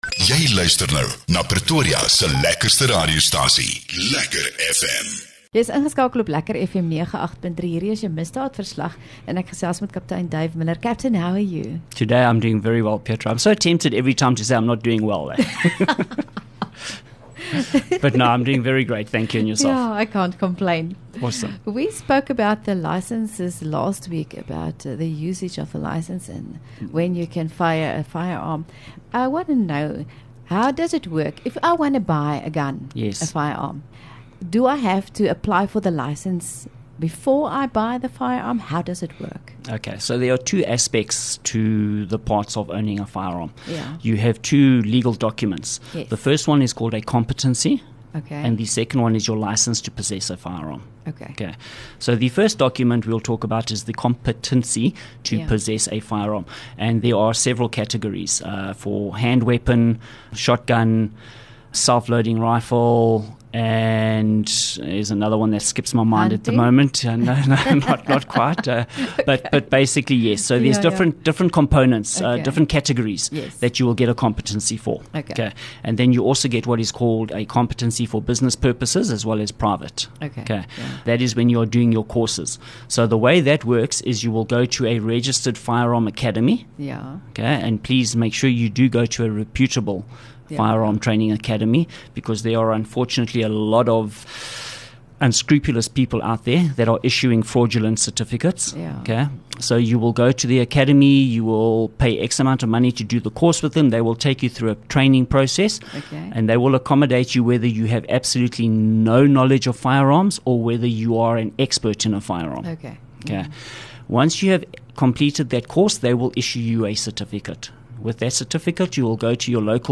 LEKKER FM | Onderhoude 1 Aug Misdaadverslag